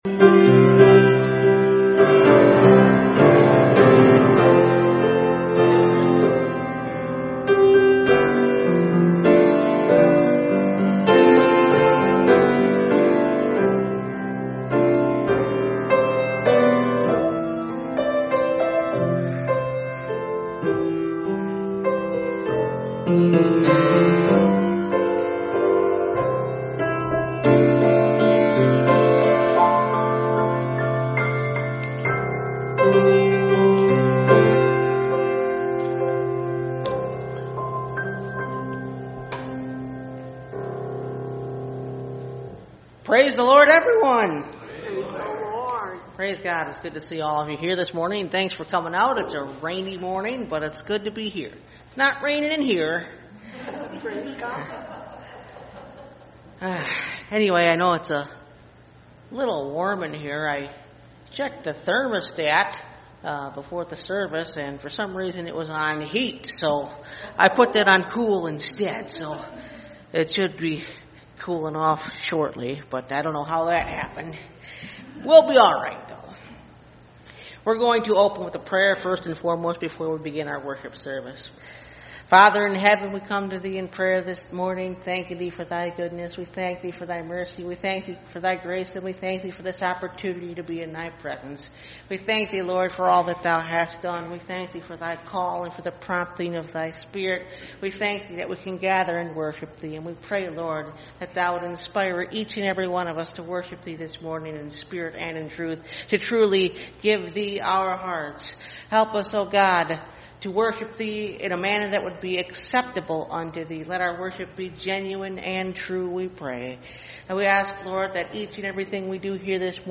Strength From Weakness – Part 3 – Last Trumpet Ministries – Truth Tabernacle – Sermon Library
Service Type: Sunday Morning